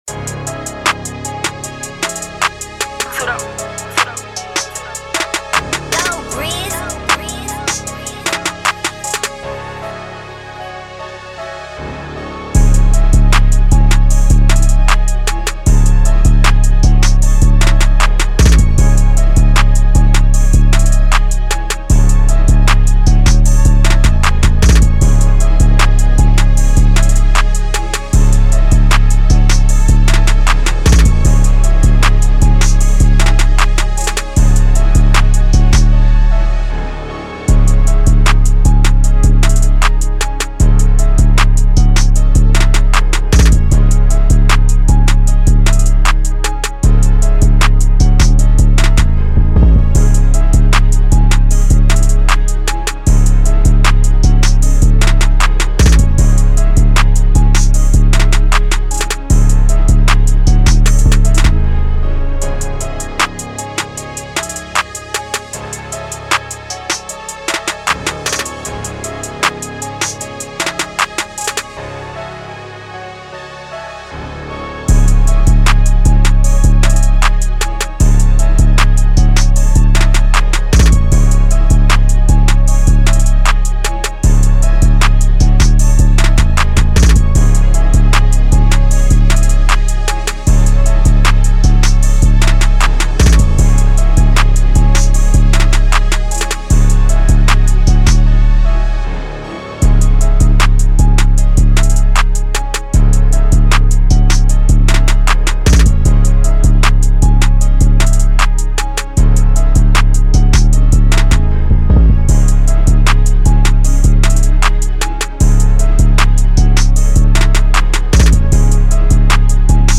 Official Instrumentals , Rap Instrumentals